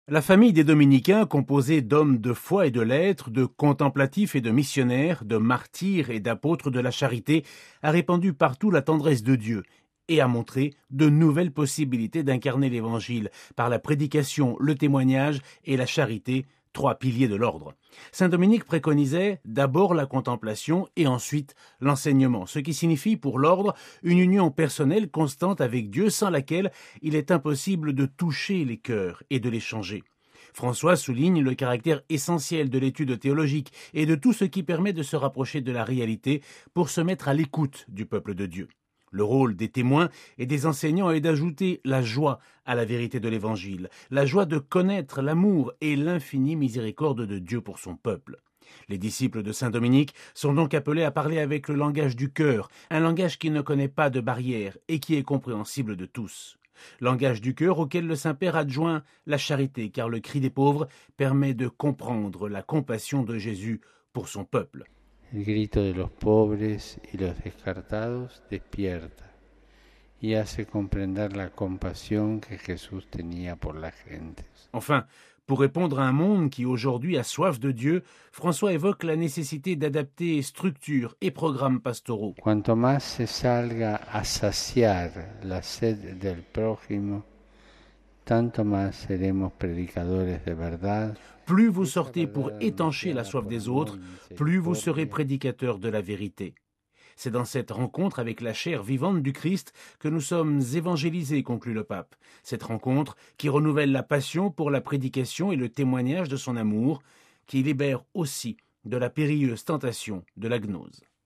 Le compte rendu